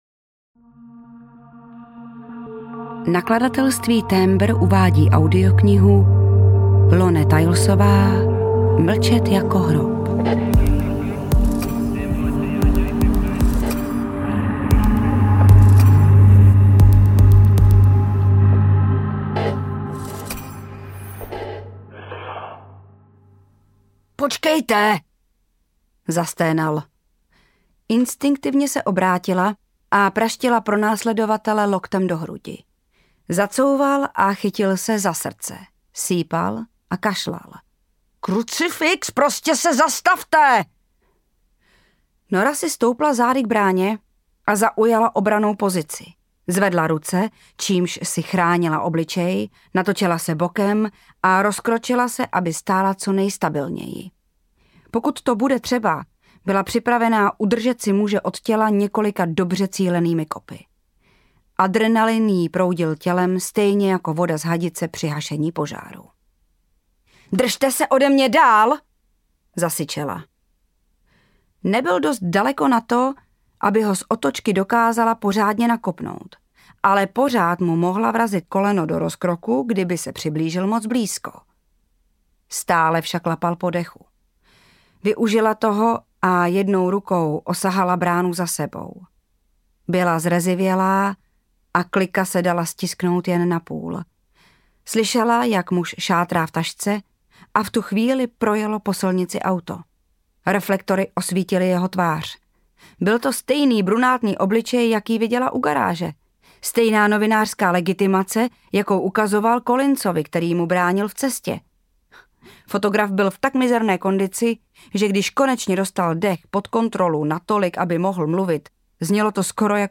Mlčet jako hrob audiokniha
Ukázka z knihy
Natočeno ve studiu BEEP